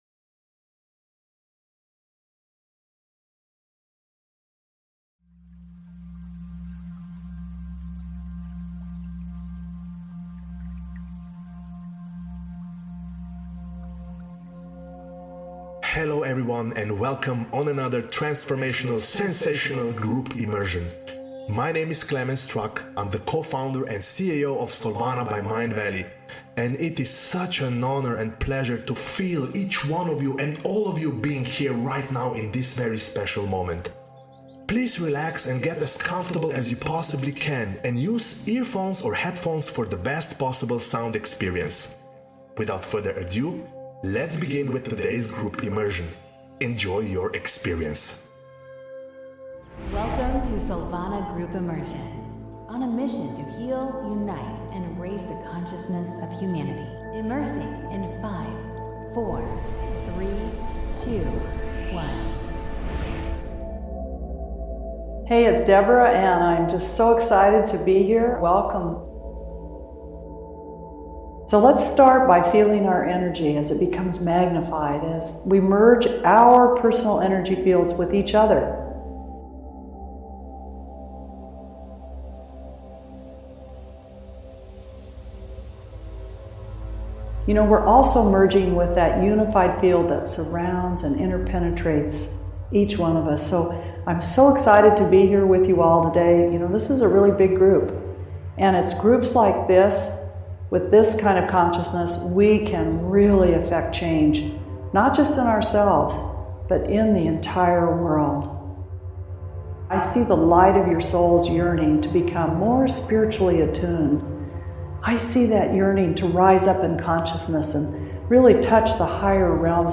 The meditation videos chosen to help access the outer chakras, use binaural beats which are designed to be listened to with headphone. The aim of them is to try and help your brain tune in to a higher frequency to assist you in accessing the outer chakras.